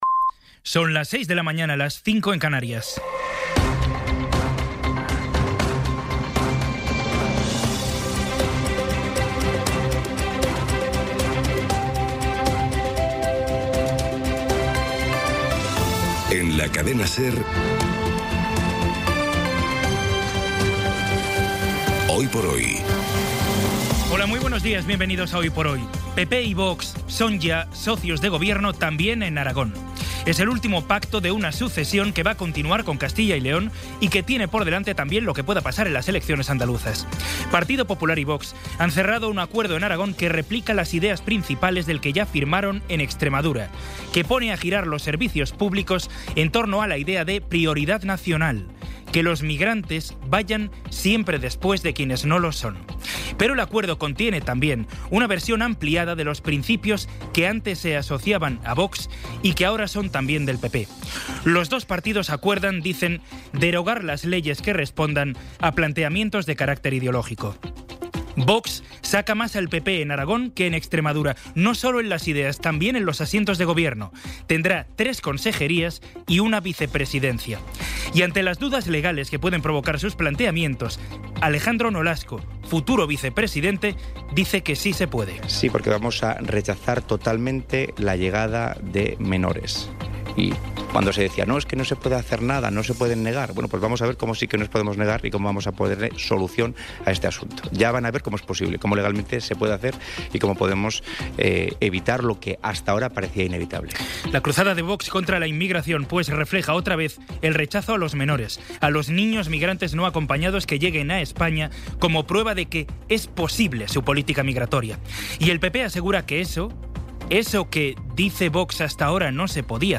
Resumen informativo con las noticias más destacadas del 23 de abril de 2026 a las seis de la mañana.